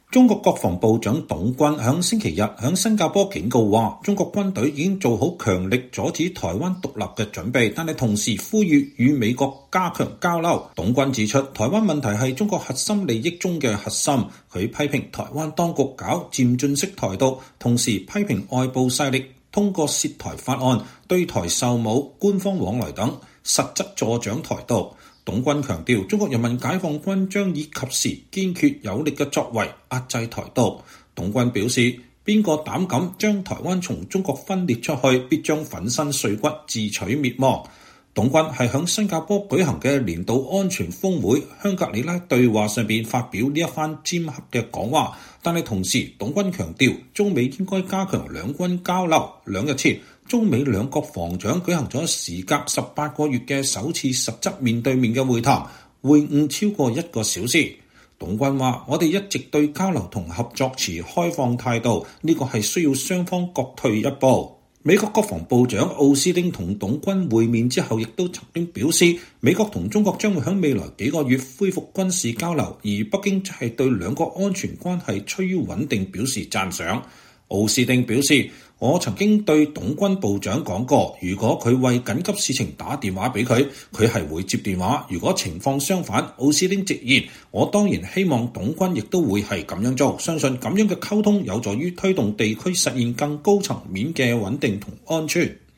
中國國防部長董軍星期日（6月2日）在新加坡“香格里拉對話”上發表講話。